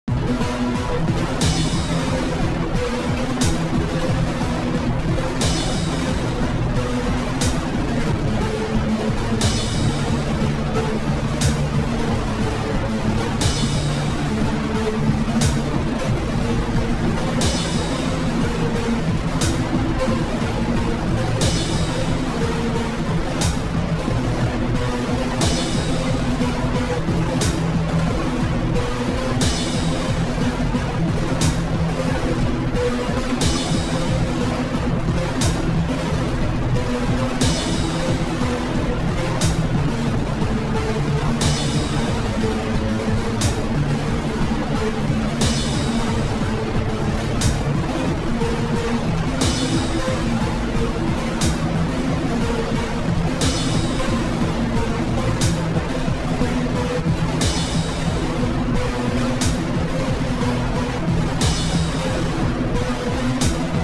then you run the audio signal through two differently tuned distortion effects and then also make it play under itself at half frequency at a delay on the order of like a measure and also distort that differently and it begins to sound like actual music (of a sort) now it’s a sad mechanical ghost dragon or a haunted crane or something